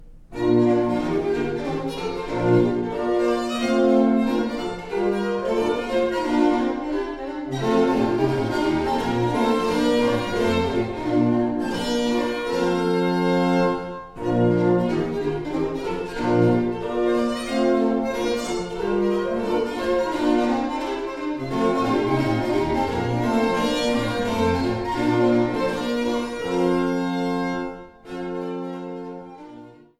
Barockgeige
Orgel